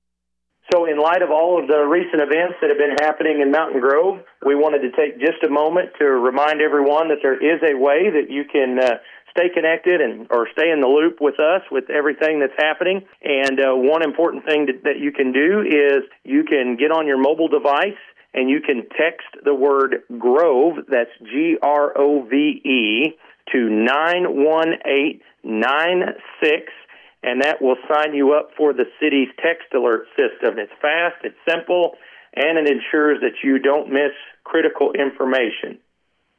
Dakota Bates, City Administrator, has more: